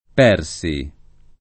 [ p $ r S i ]